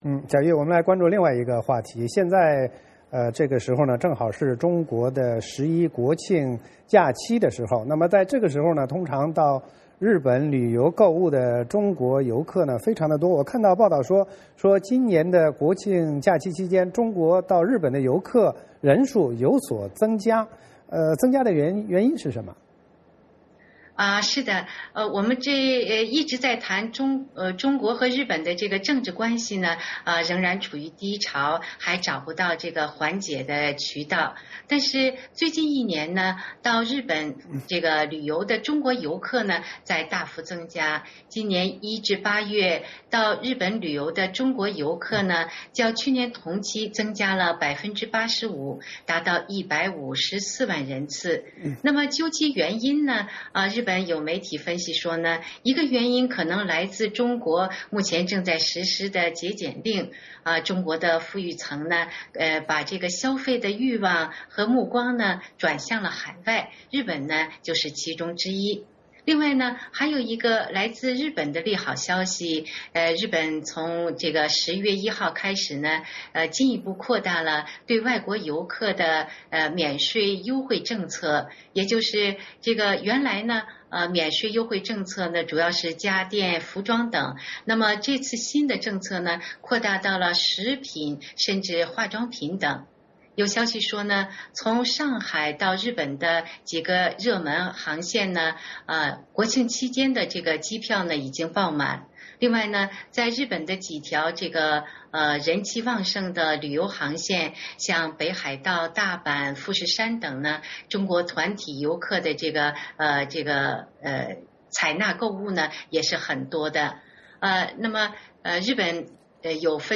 VOA连线：“十一”中国赴日游客人数增长